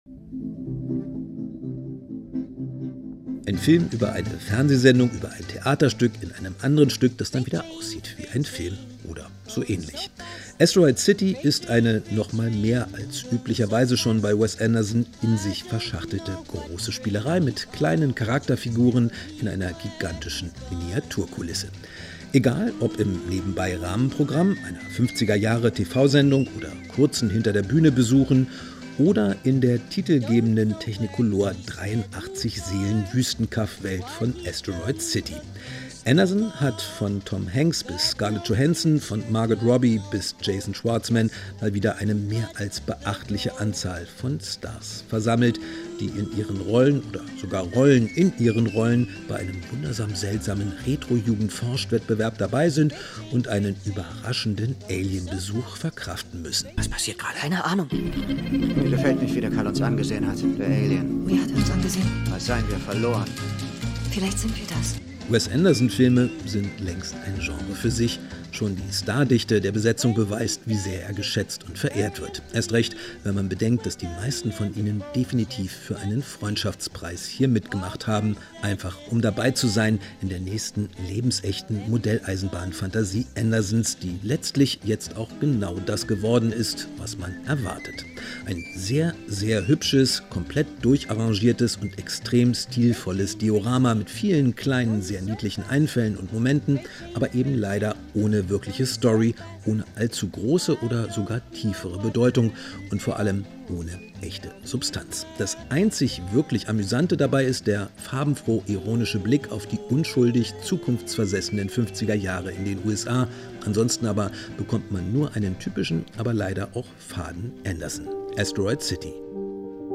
Inforadio Nachrichten, 30.07.2023, 21:00 Uhr - 30.07.2023